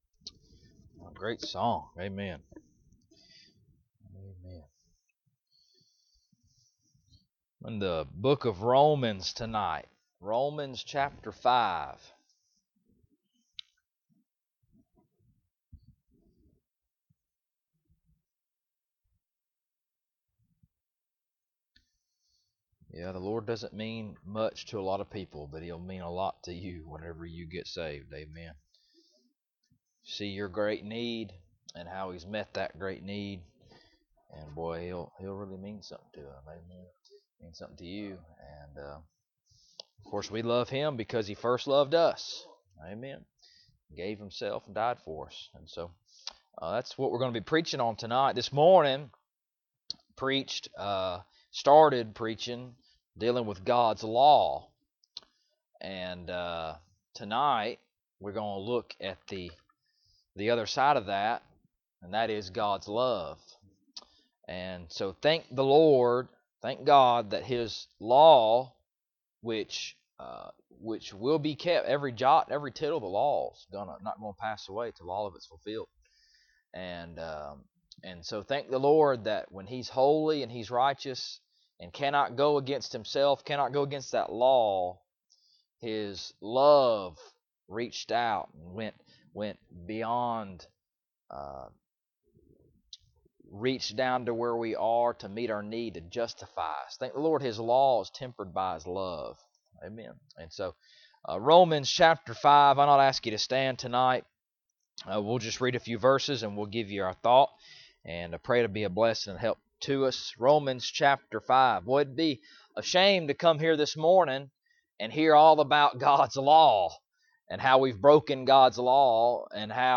Romans 5:6-11 Service Type: Sunday Evening Bible Text